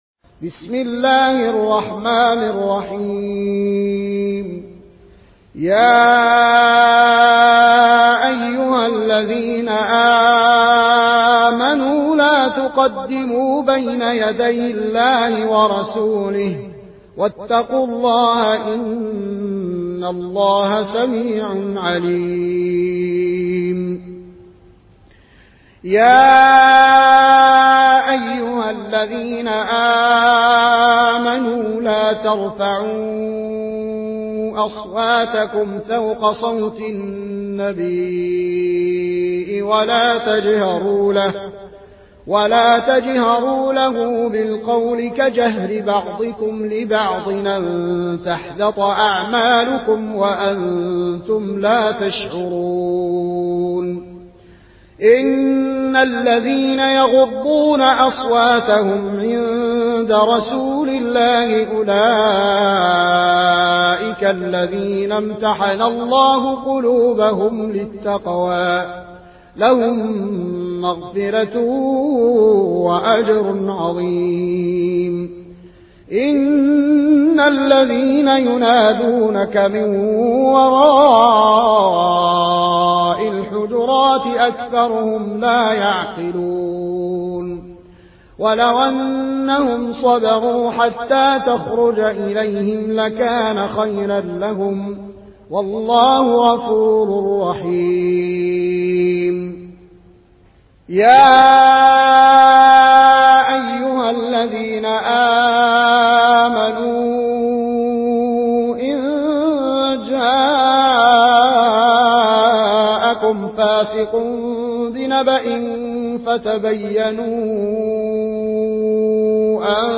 Riwayat Warsh dari Nafi